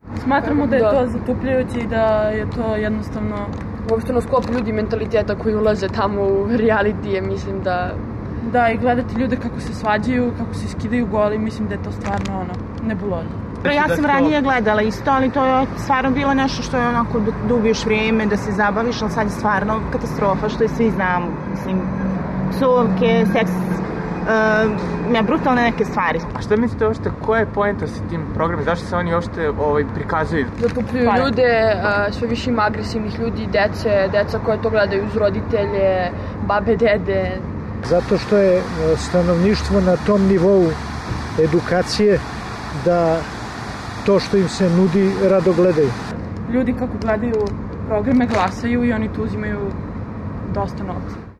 Beograđanke i Beograđani sa kojima smo razgovarali kažu da nisu gledaoci rijaliti emisija: